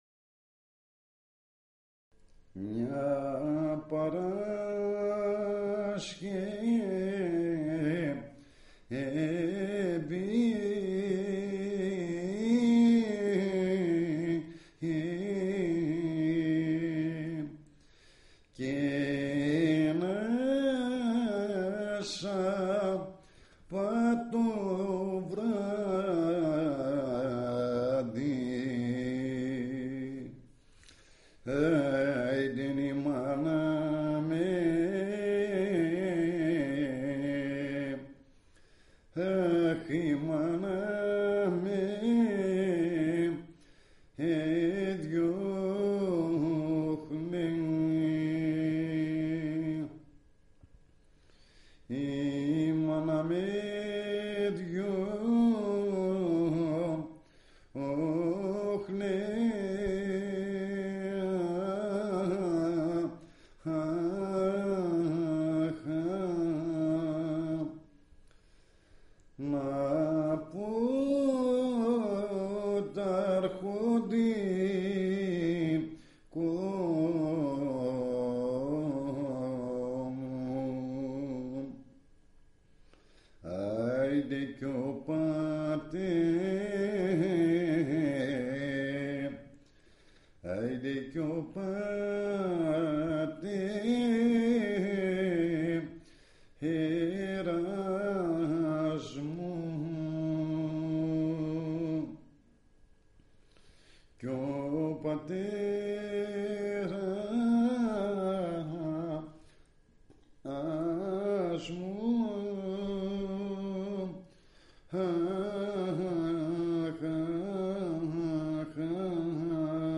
Track 3 στο cd της επιτόπιας έρευνας).